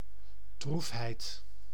Ääntäminen
IPA : /spaɪt/